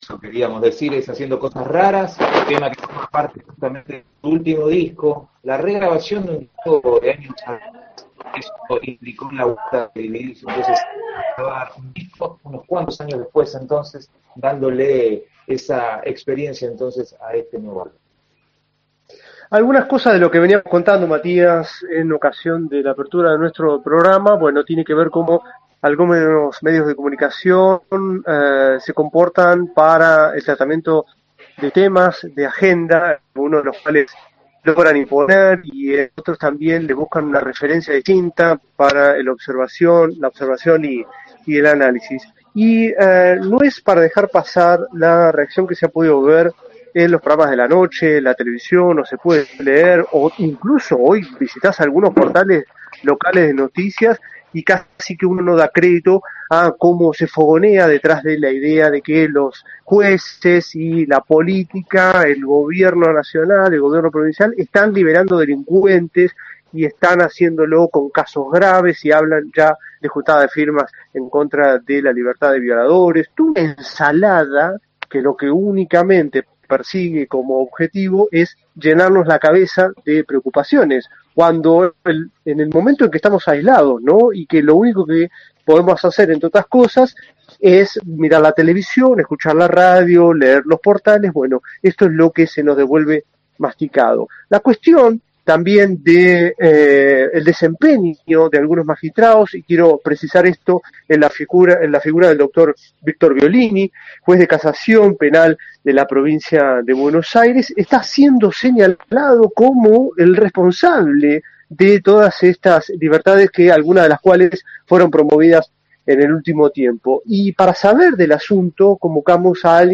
La entrevista completa